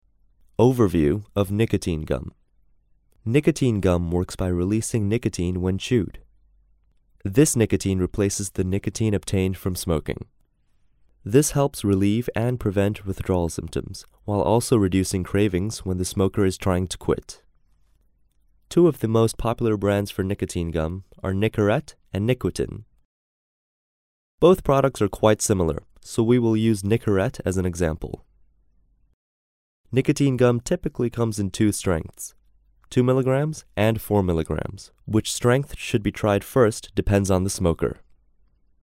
Narration audio (MP3)